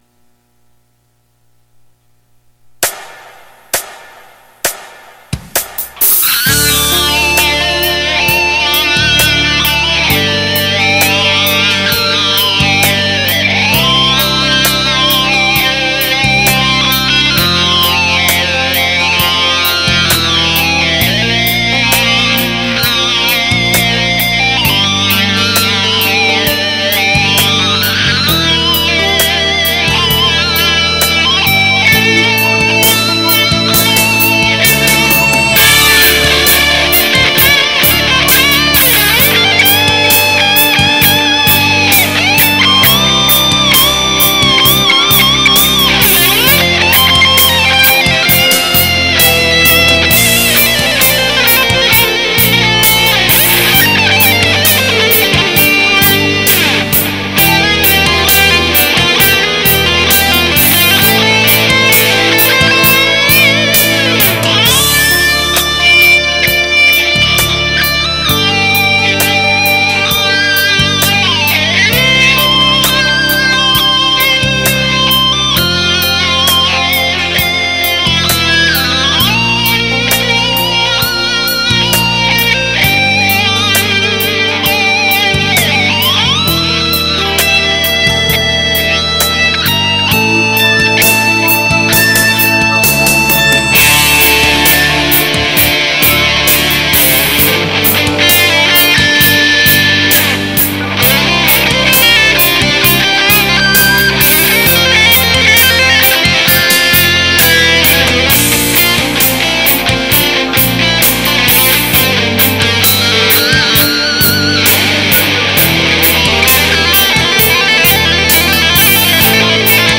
Pre Recorded Backing Track